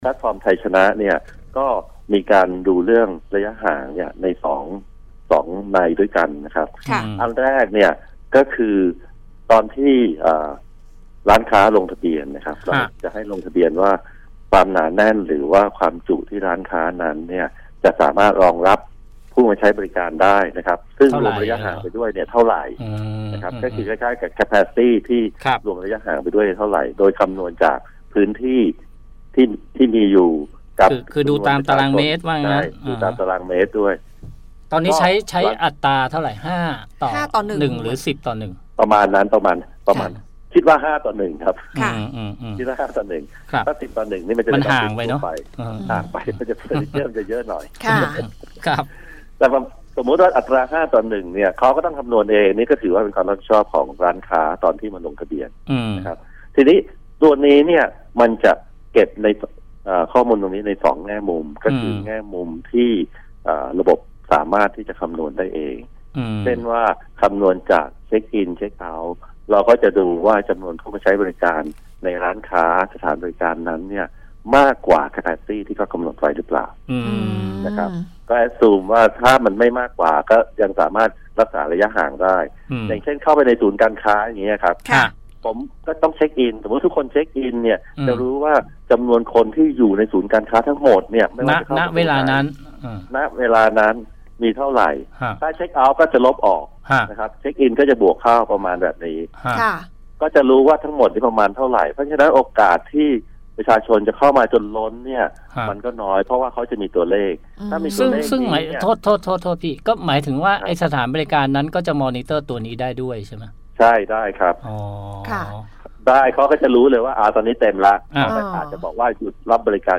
สัมภาษณ์